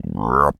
frog_deep_croak_02.wav